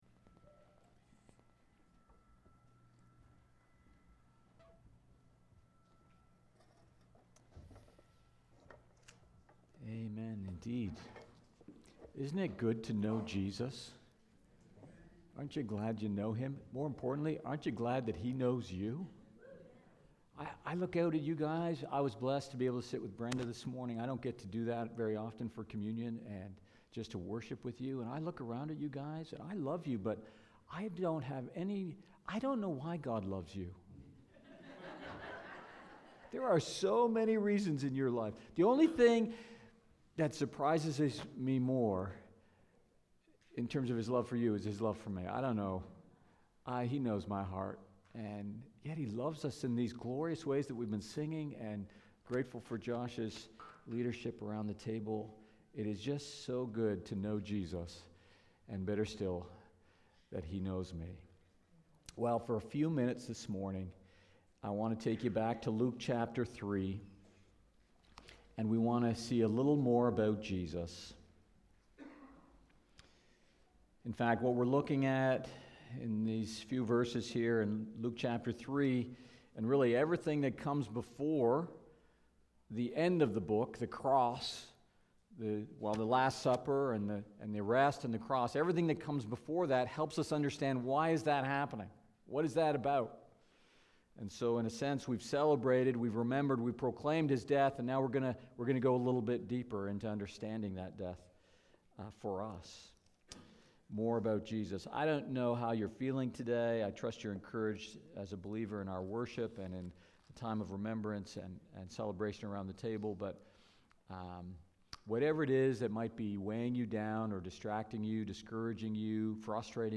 Sermons | Port Perry Baptist